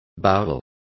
Complete with pronunciation of the translation of bowel.